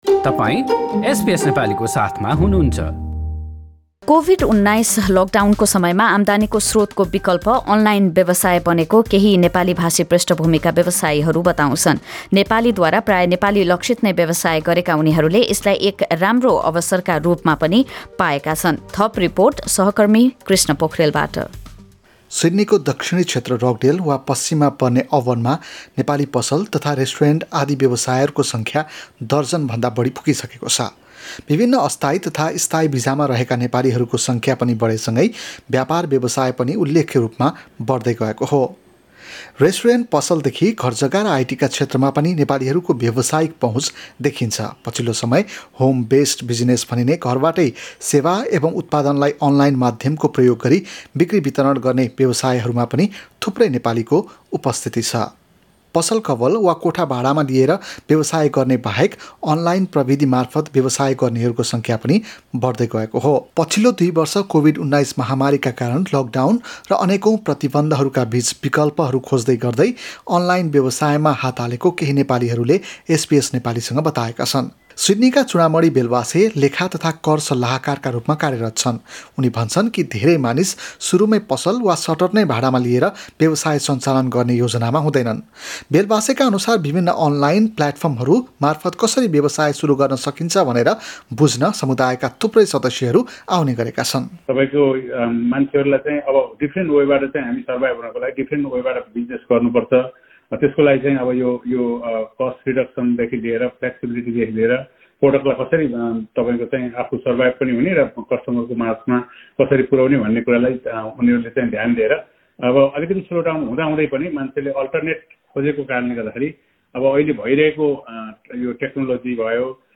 Listen to our conversation with some Nepali online business owners in Australia: Nepali people in Australia who started their own online business during Covid-19 Click here to listen to our podcasts free-of-cost.